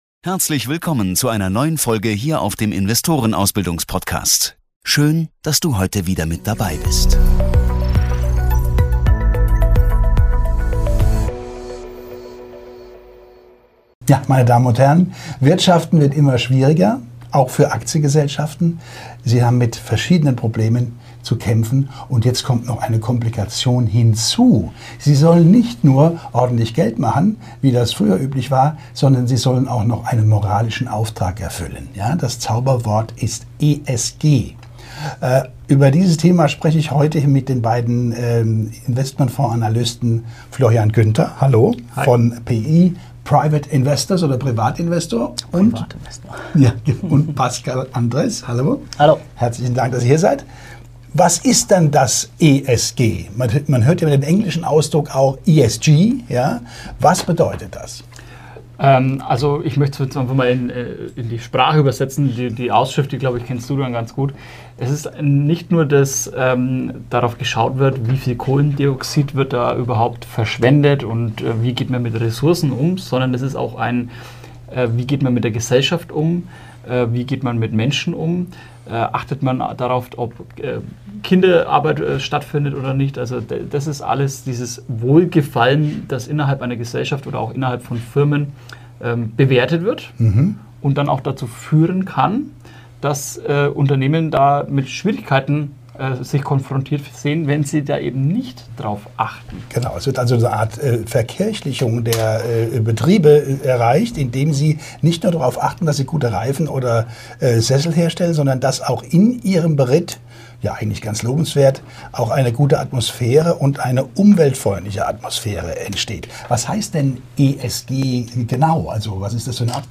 Die Experten diskutieren kritisch über die Vor- und Nachteile von ESG-Kriterien, deren Einfluss auf Unternehmensperformance und Investmentstrategien.